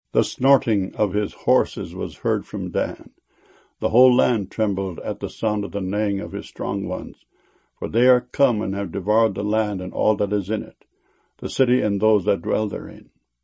snorting.mp3